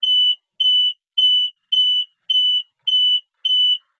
PlayerLowHealthAlarm.wav